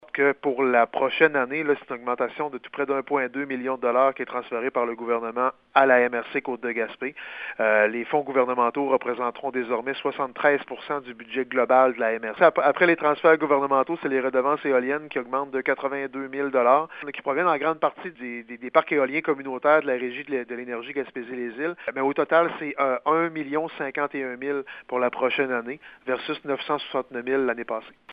Le maire de Gaspé et préfet de la MRC de La Côte-de-Gaspé, Daniel Côté, s'est adressé aux médias par téléphone et vidéoconférence ce matin.